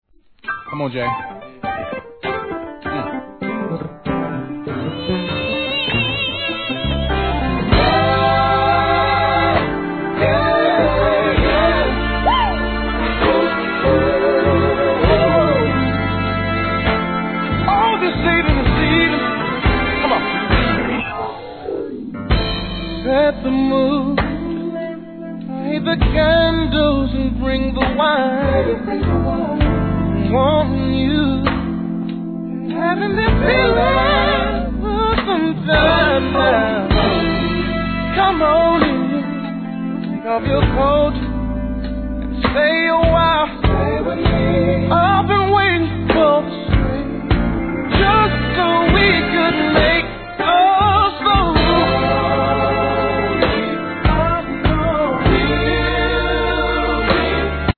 HIP HOP/R&B
生音でのSLOWナンバーは抜群の歌唱力を堪能できます♪